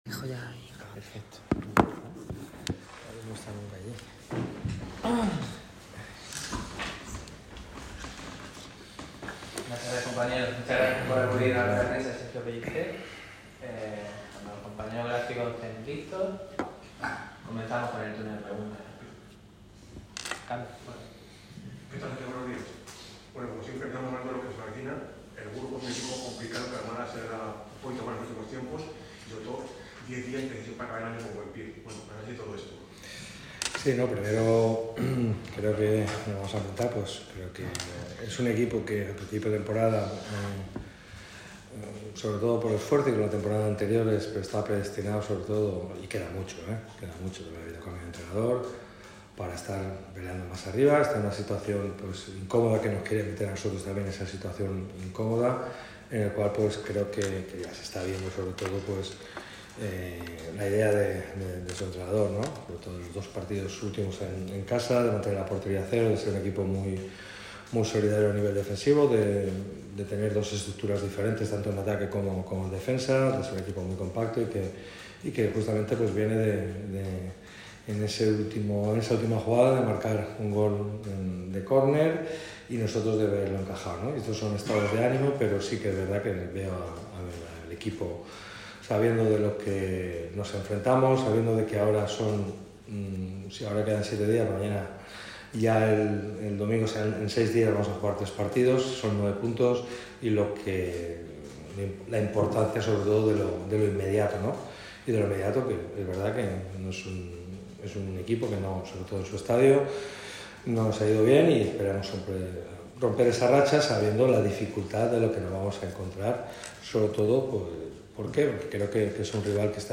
ha comparecido ante los medios en rueda de prensa con motivo de la previa del Burgos CF-Mákaga CF que se disputará este domingo a las 16:15 horas. Donde los blanquiazules buscarán acabar con la mala racha de cinco partidos sin conocer la victoria.